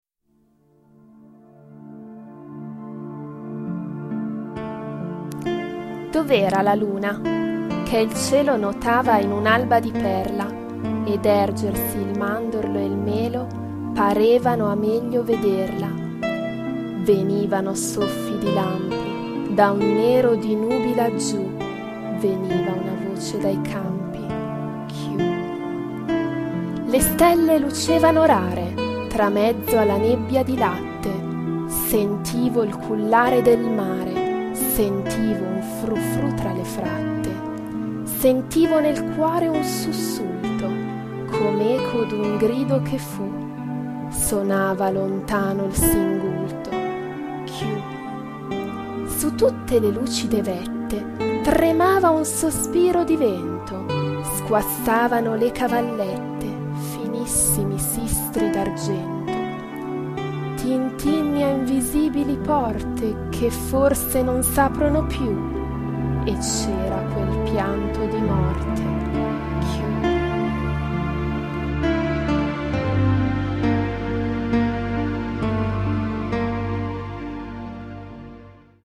Inserito in Poesie recitate da docenti